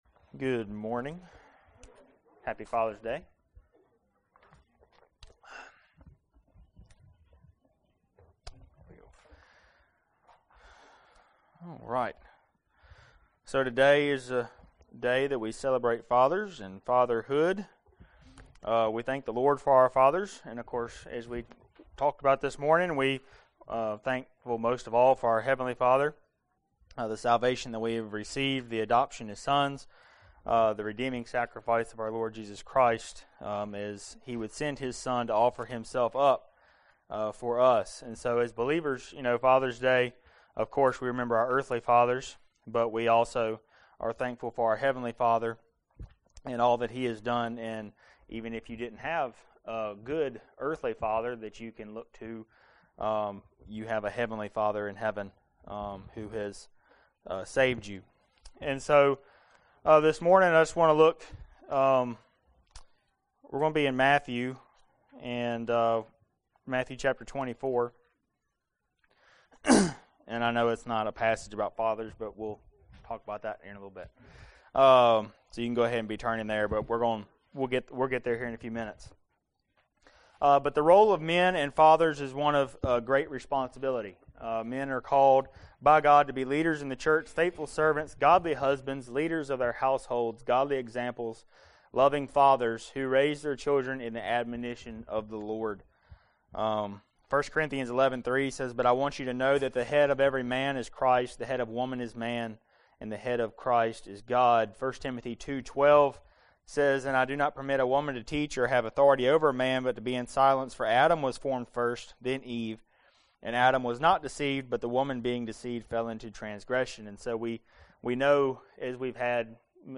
Passage: Matthew 24:45-47 Service Type: Sunday Morning Related « The Power